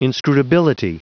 Prononciation du mot inscrutability en anglais (fichier audio)
Prononciation du mot : inscrutability